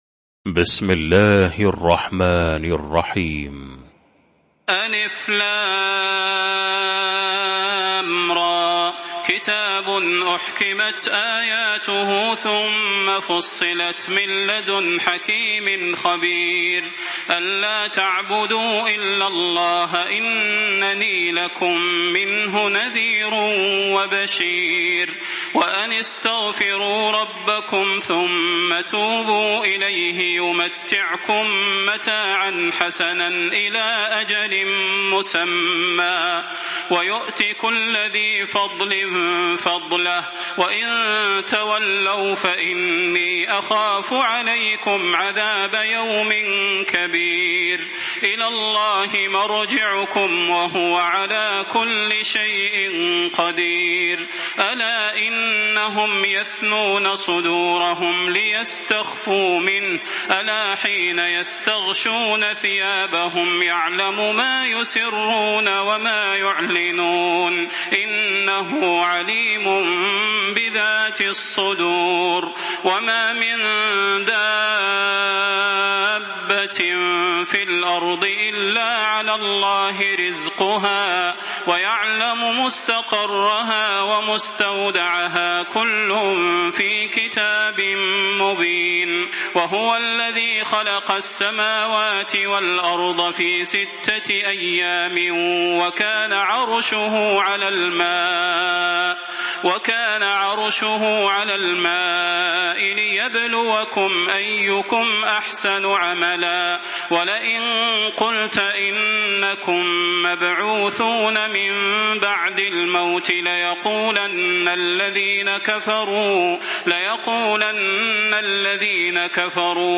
المكان: المسجد النبوي الشيخ: فضيلة الشيخ د. صلاح بن محمد البدير فضيلة الشيخ د. صلاح بن محمد البدير هود